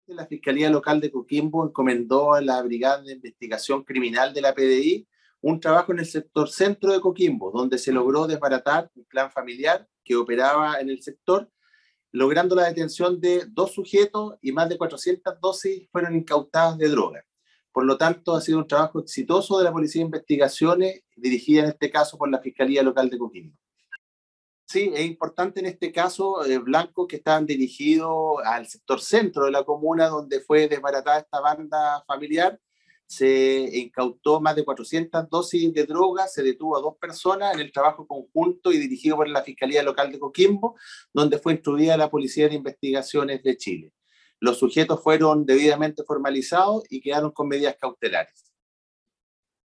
AUDIO : Fiscal jefe de Coquimbo Claudio Correa
CUNA-FISCAL.mp3